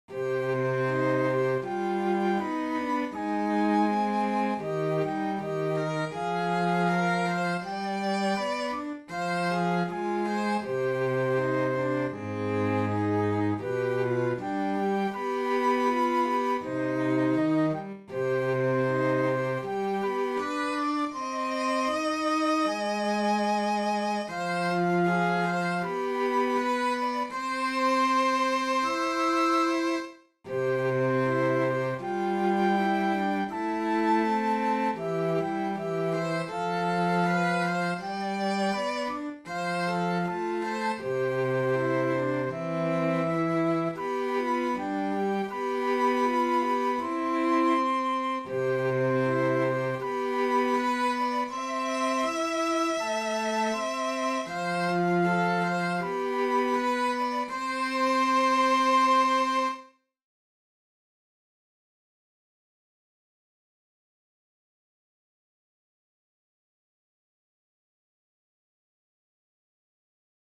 Sorsa-ja-joutsen-sellot-ja-huilu.mp3